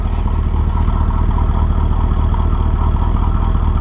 第2部分：射击 " silencerimpact2
描述：拍摄西瓜皮，金属枝形吊灯安装盖，以及距离大约25英尺的钢制bb的旧骨头。这可以作为抑制.22效果的基础。
标签： 射击 消声器 冲击
声道立体声